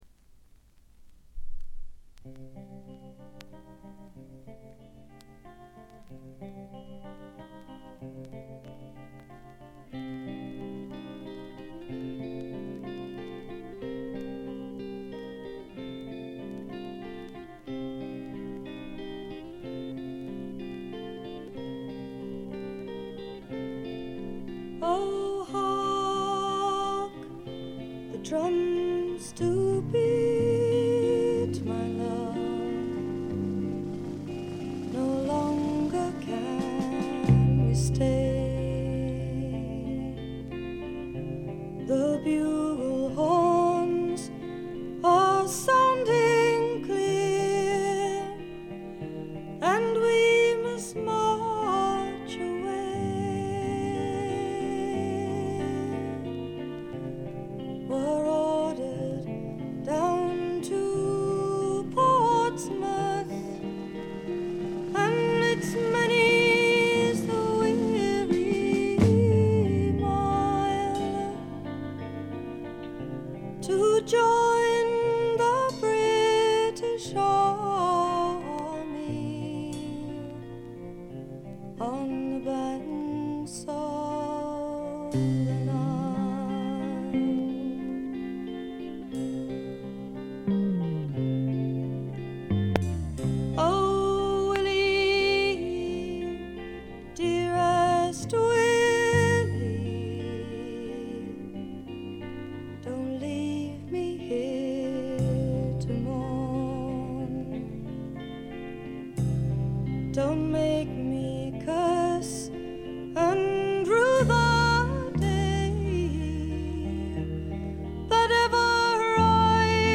軽微なバックグラウンドノイズ、チリプチ、散発的な軽いプツ音が少し。
英国フォークロックの基本中の基本！！
試聴曲は現品からの取り込み音源です。